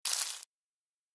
دانلود آهنگ جنگل 21 از افکت صوتی طبیعت و محیط
جلوه های صوتی
دانلود صدای جنگل 21 از ساعد نیوز با لینک مستقیم و کیفیت بالا